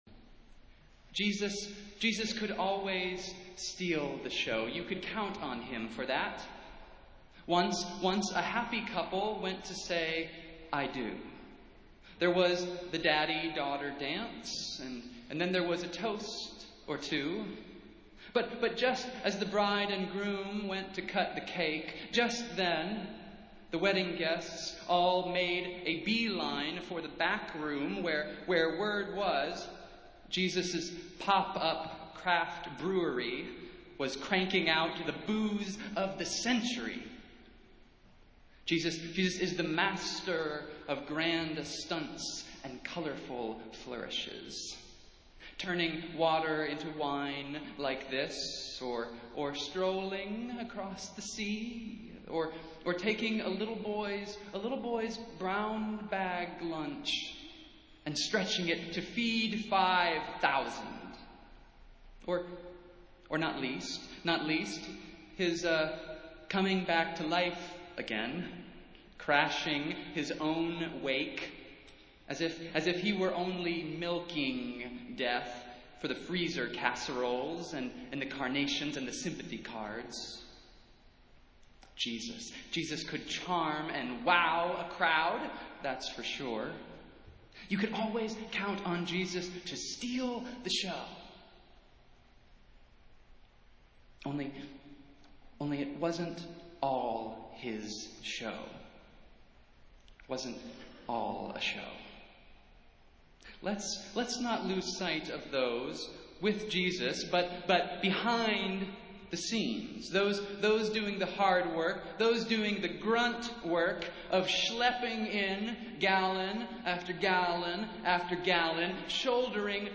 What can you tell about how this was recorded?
Festival Worship - Congregational Care and Support Sunday